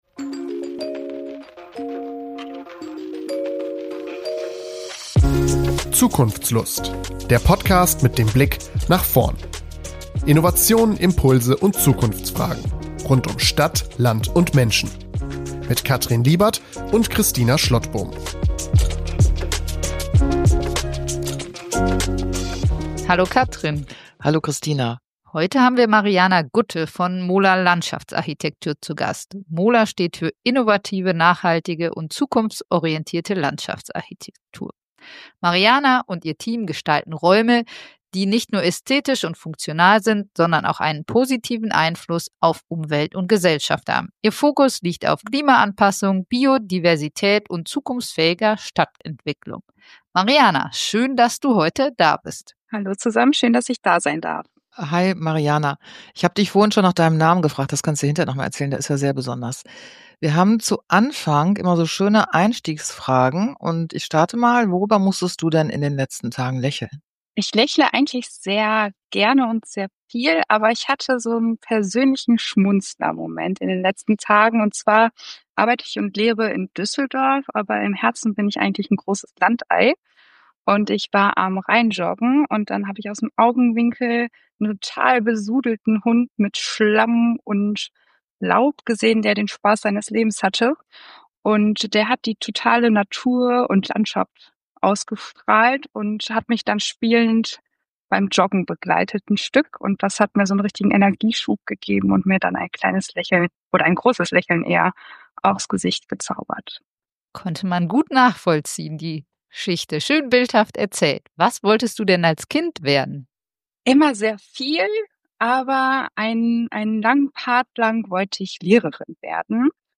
Außerdem werfen wir einen Blick in die Zukunft: Welche Trends und Entwicklungen prägen die Landschaftsarchitektur? Ein inspirierendes Gespräch für alle, die sich für nachhaltige Stadtentwicklung, Klimaschutz und kreative Lösungen begeistern!